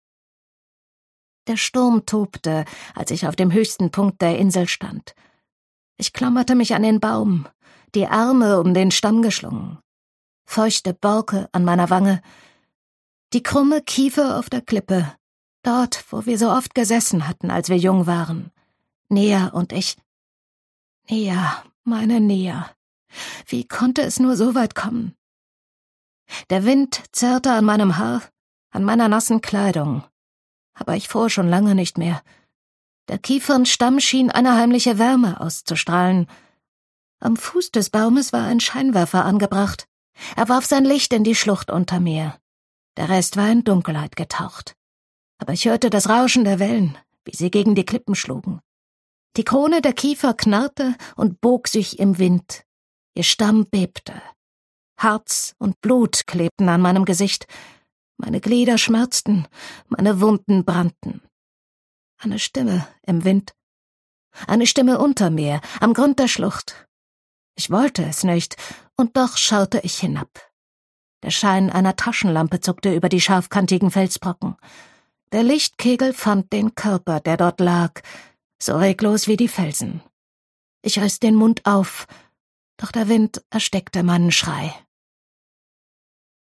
Ausgabe: Ungekürzte Lesung